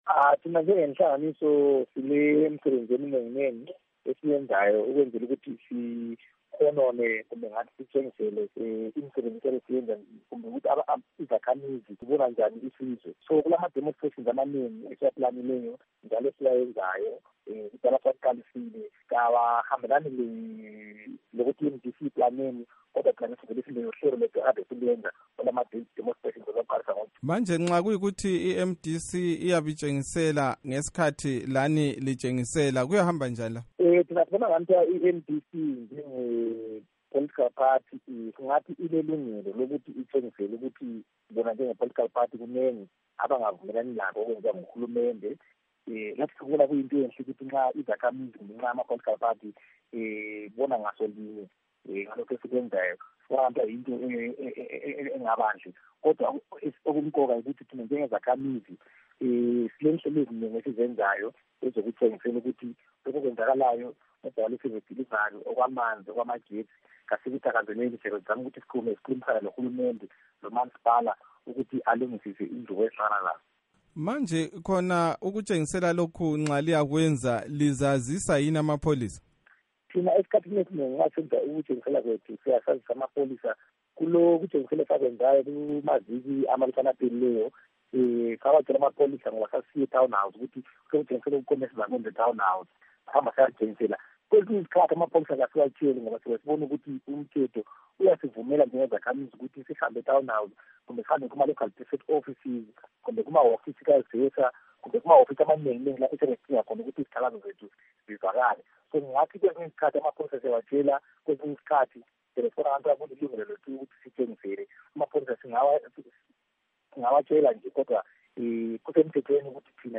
Embed share Ingxoxo loMnu.